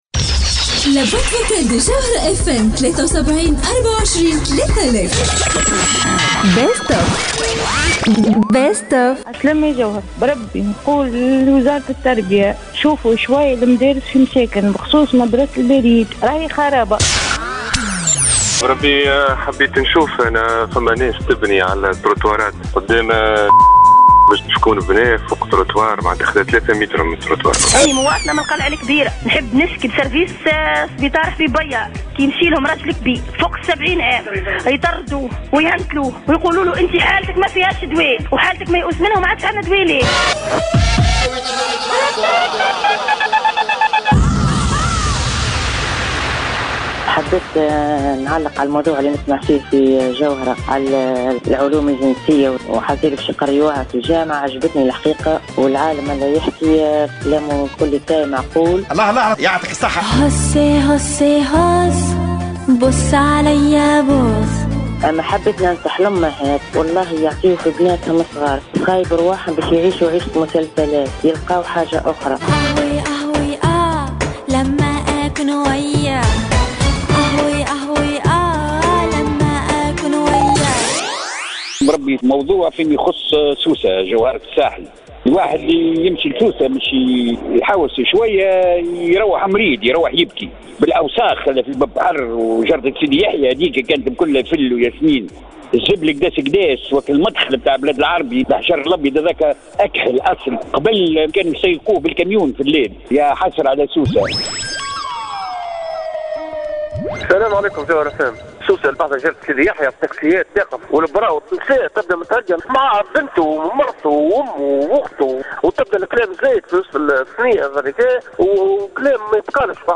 مواطنة : بربي شوفو حالة المدارس في مساكن خاصة مدرسة البريد